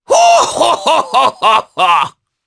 Oddy-Vox_Happy3_jp.wav